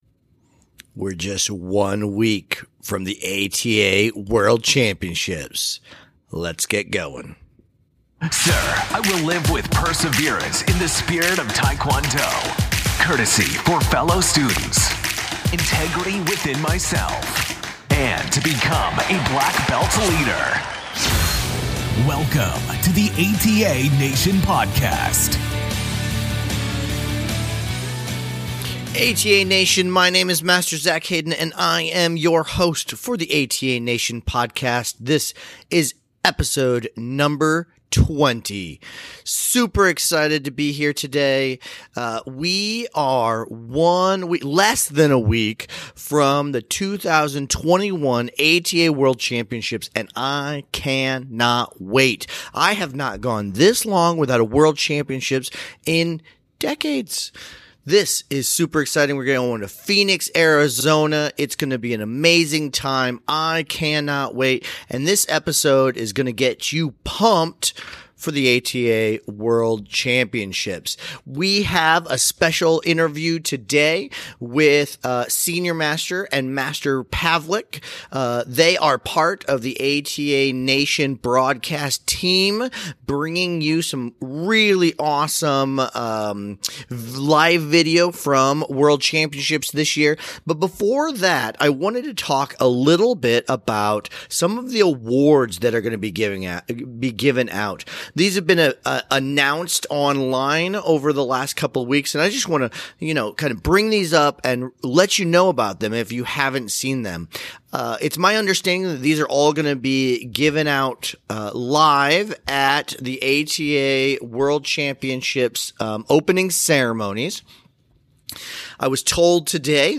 We are DAYS away from the 2021 ATA World Championships. In today's episode we go over all the nominations for awards that will be given out at the Opening Ceremonies as well as interview two of the broacasters on the ATA Nation Network.